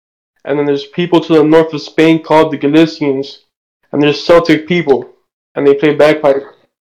Bagpipe 2